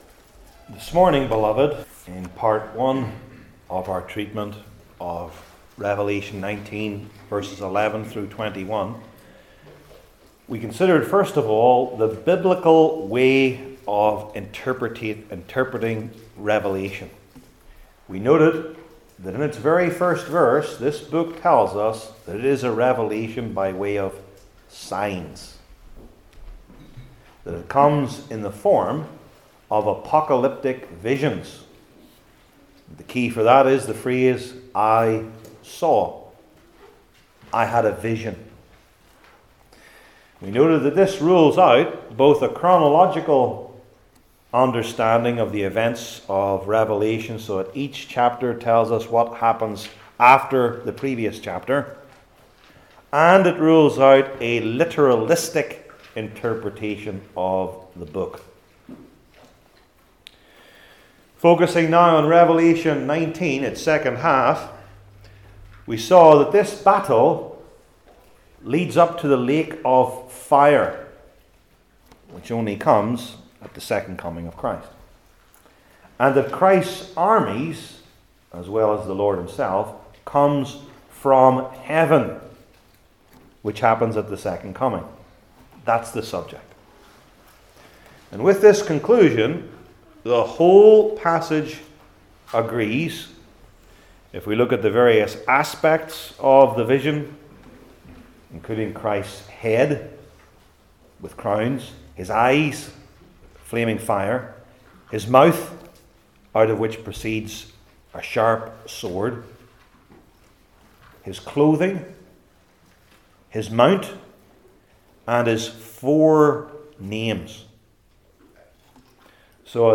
Revelation 19:17-21 Service Type: New Testament Individual Sermons I. The Great Supper II.